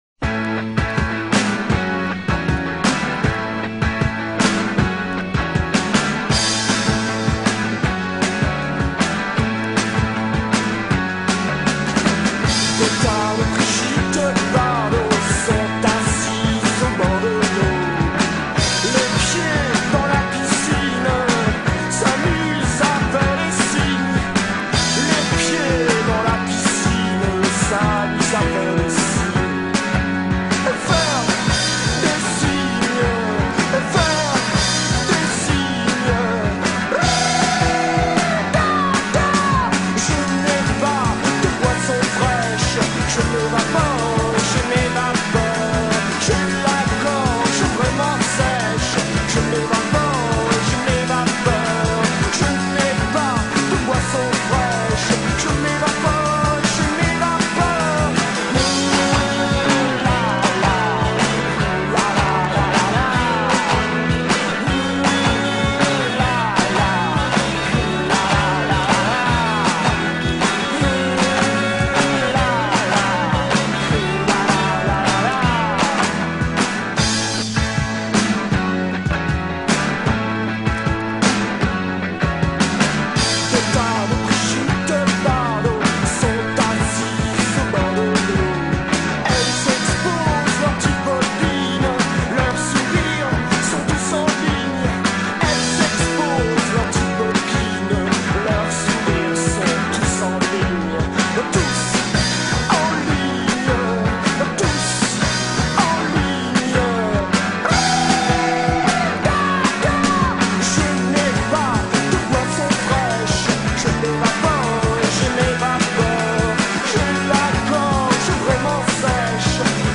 le groupe de rock français